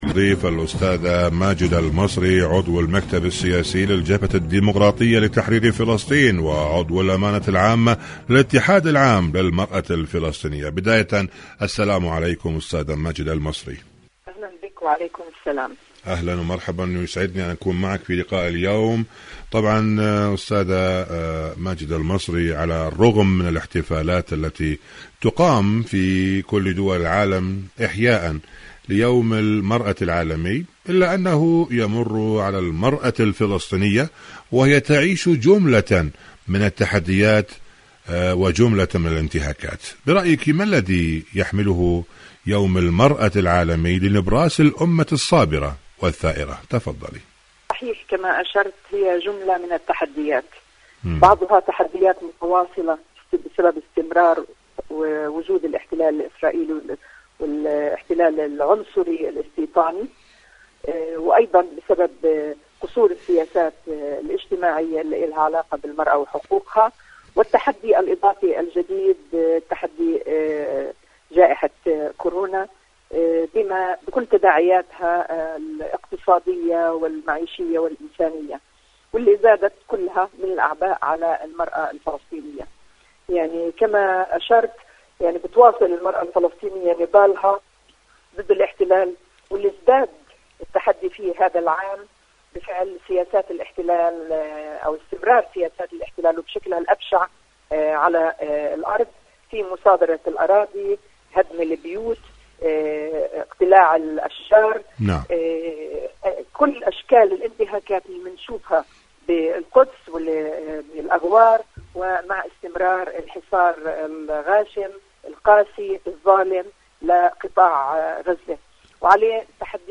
إذاعة طهران-فلسطين اليوم: مقابلة إذاعية مع ماجدة المصري عضو المكتب السياسي للجبهة الديمقراطية لتحرير فلسطين حول موضوع المرأة الفلسطينية مهد المحبة وحكاية الصبر.
مقابلات إذاعية